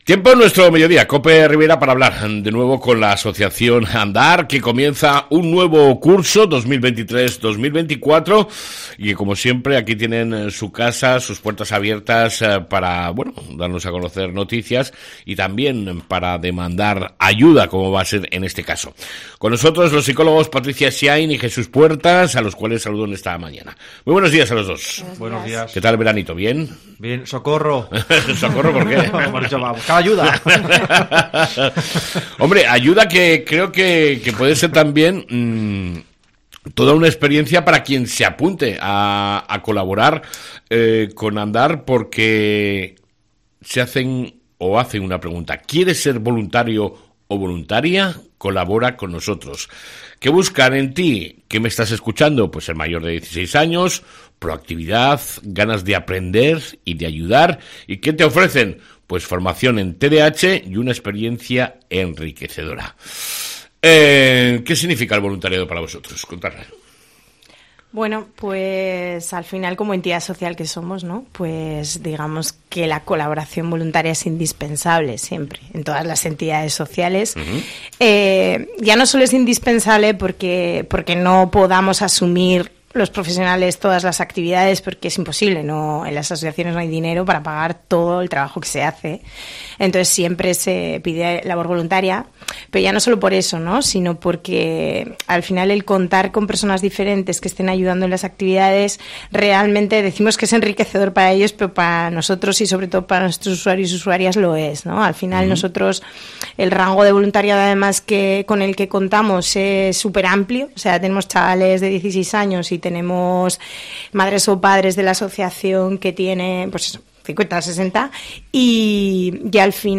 ENTREVISTA CON LA ASOCIACIÓN ANDAR